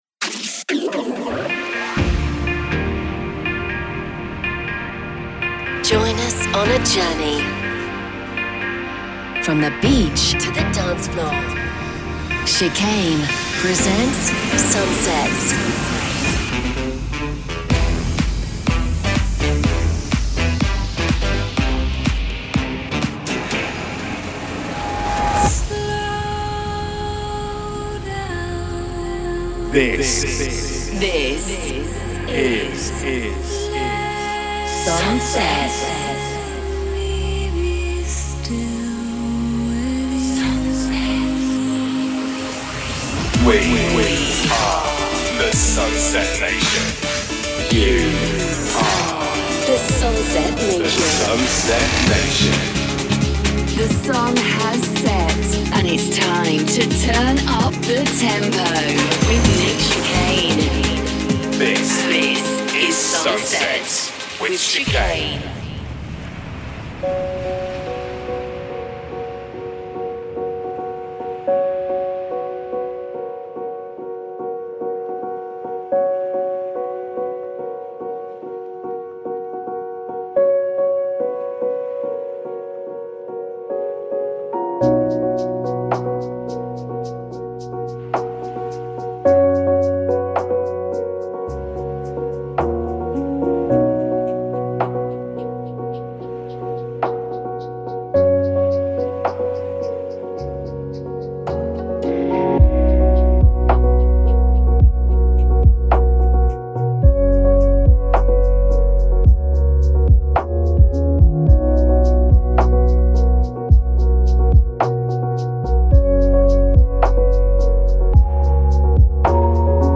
From the beach to the dancefloor...